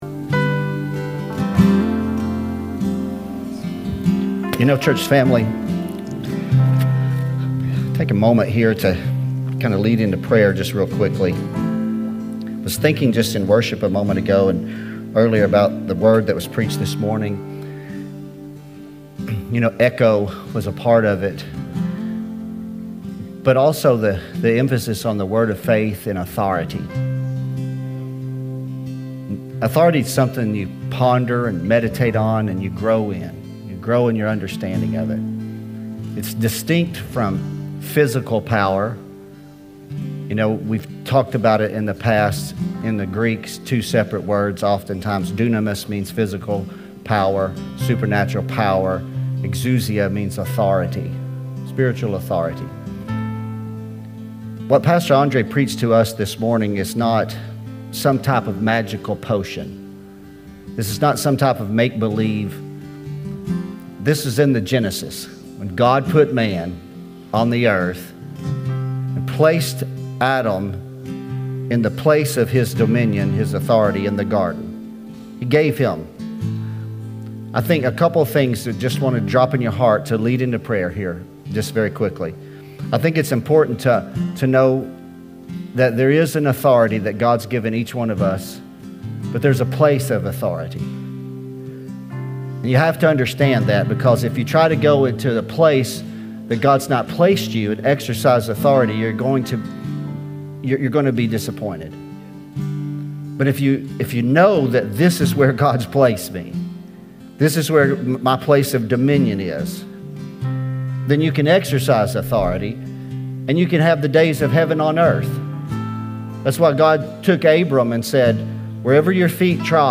2025 Evening Service